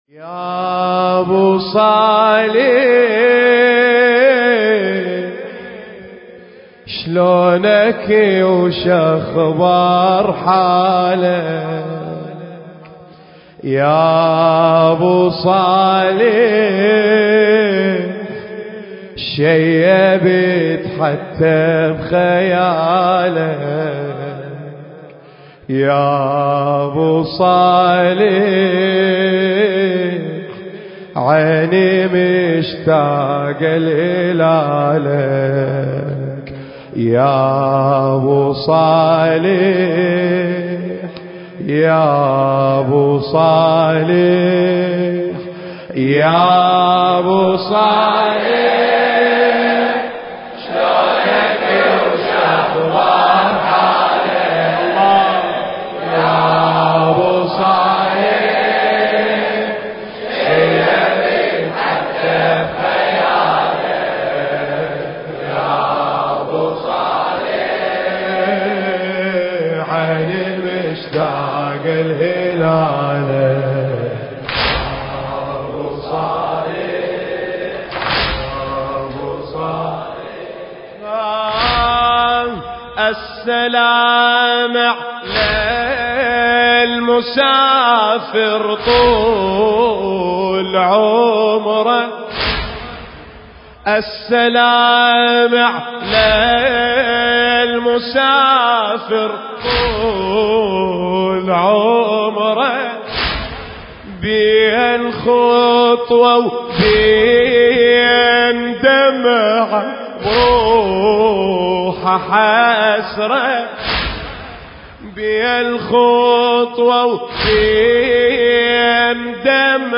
المكان: جامع وحسينية الإمام الرضا (عليه السلام) – البصرة